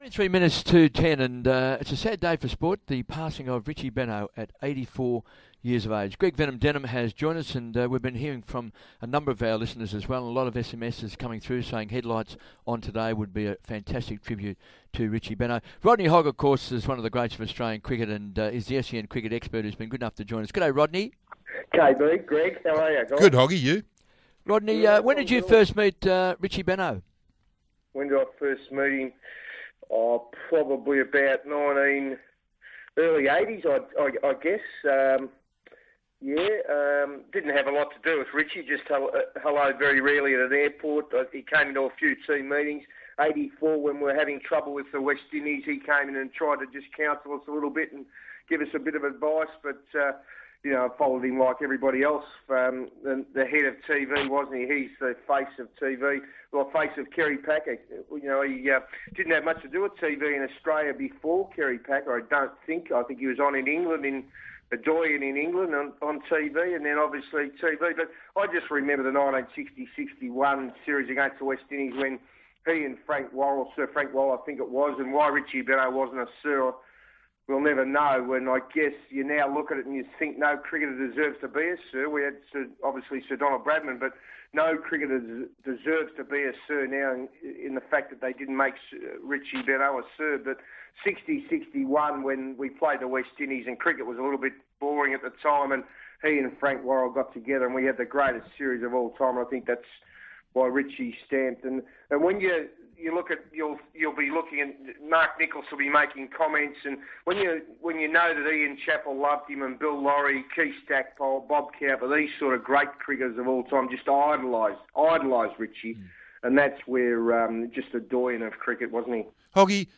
Rodney Hogg joins Kevin Bartlett on the sad passing of Richie Benaud this morning